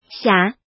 怎么读
xiǎ